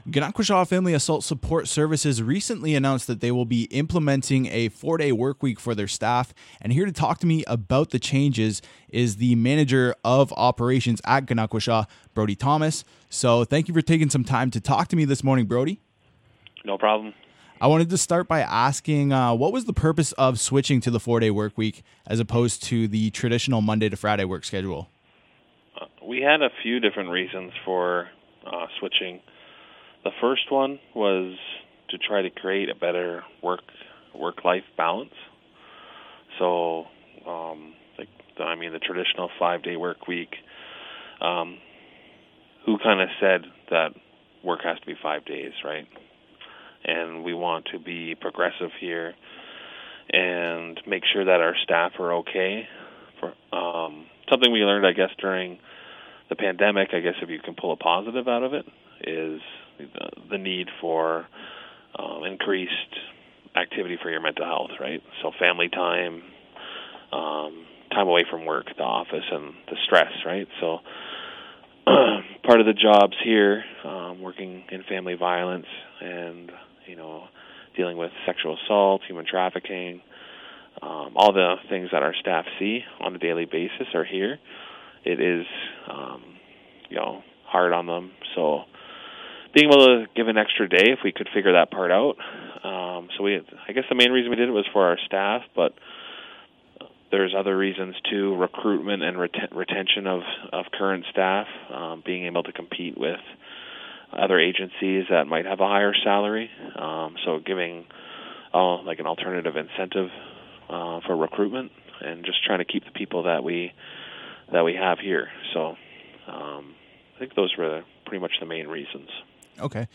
ganohkwasra-interview-jun-10.mp3